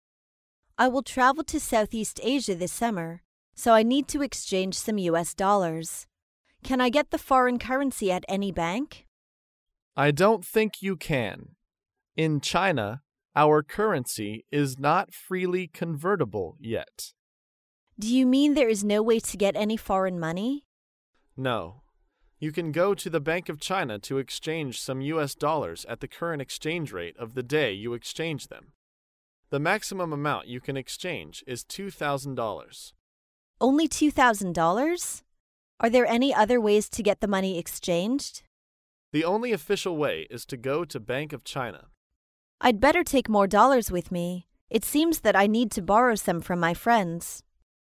在线英语听力室高频英语口语对话 第435期:人民币兑换美元的听力文件下载,《高频英语口语对话》栏目包含了日常生活中经常使用的英语情景对话，是学习英语口语，能够帮助英语爱好者在听英语对话的过程中，积累英语口语习语知识，提高英语听说水平，并通过栏目中的中英文字幕和音频MP3文件，提高英语语感。